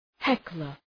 {‘heklər}